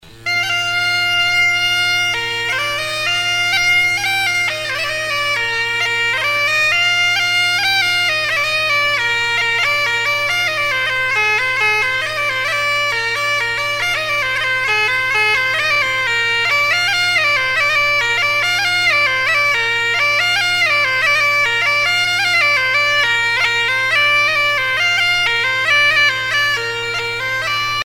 Suite de danses du Pays nantais
Usage d'après l'analyste gestuel : danse